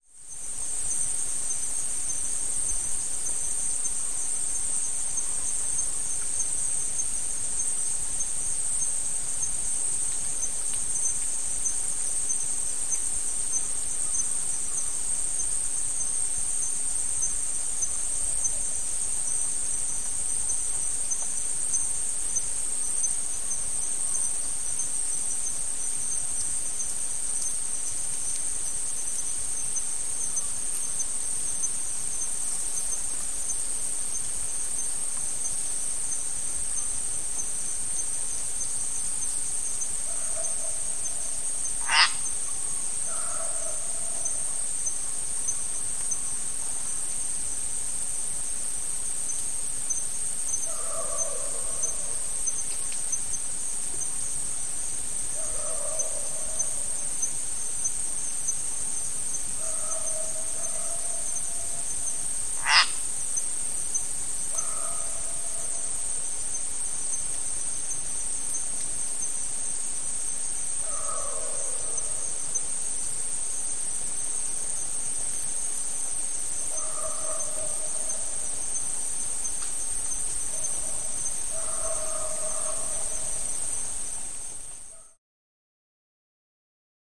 Тихая атмосфера тропического леса (записано ближе к 16:00)